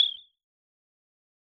bird.wav